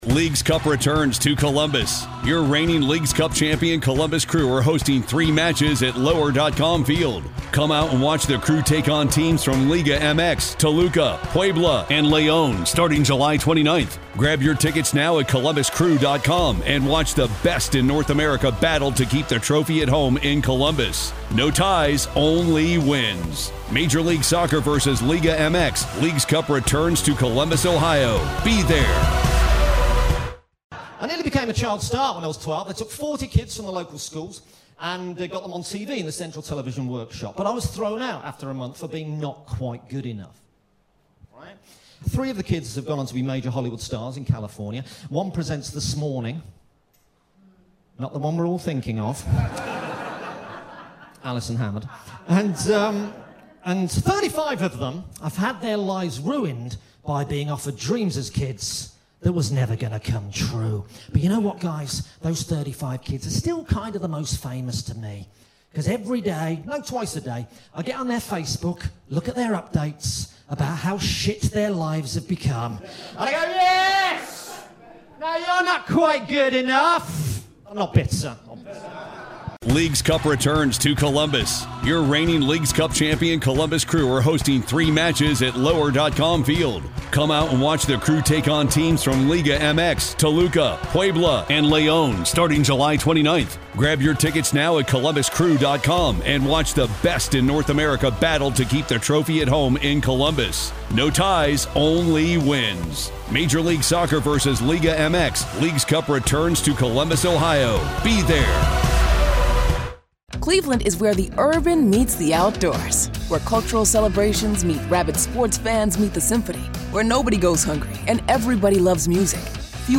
Recorded Live at Edinburgh Fringe.